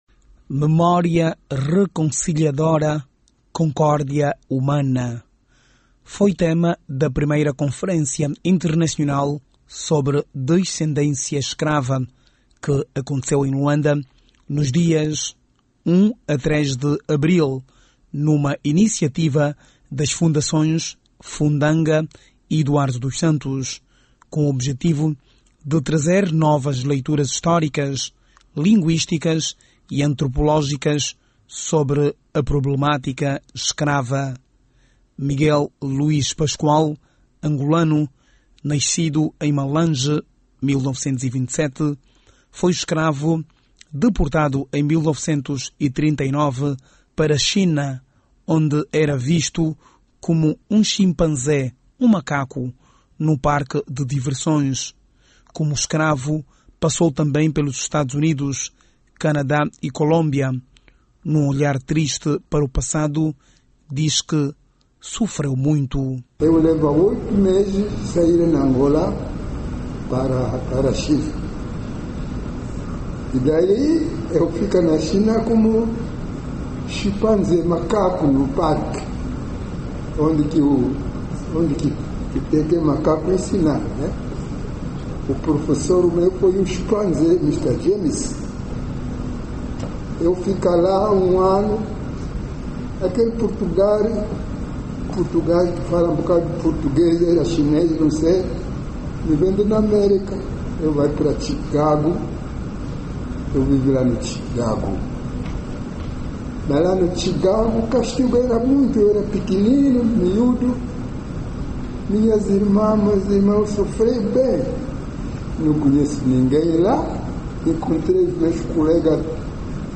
Conferência sobre escravatura em Luanda